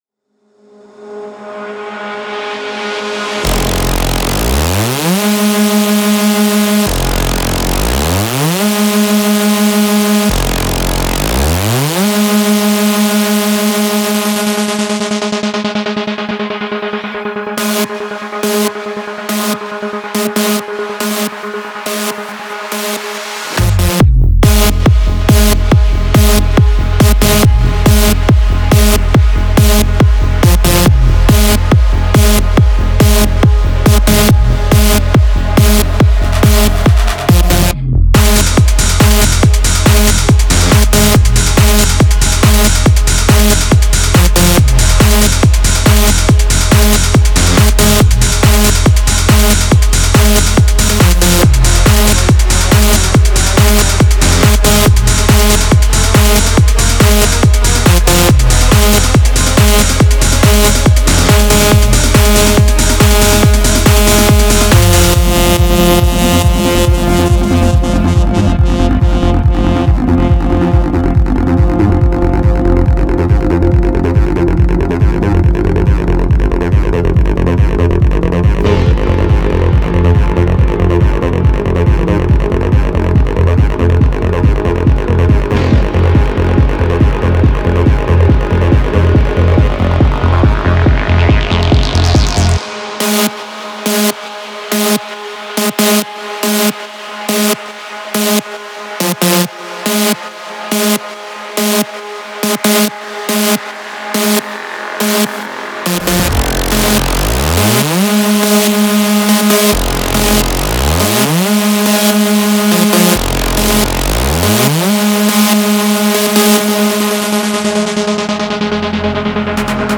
• Жанр: EDM